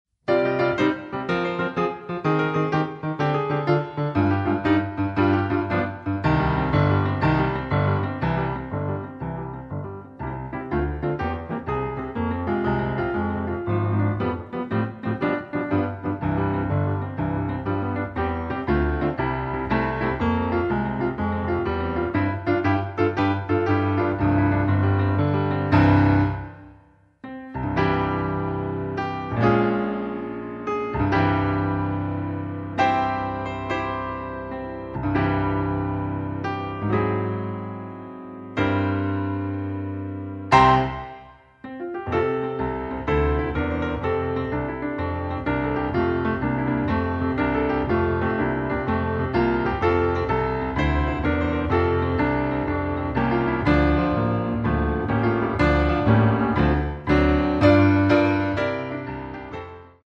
Bari/Bass
(accompaniment excerpt)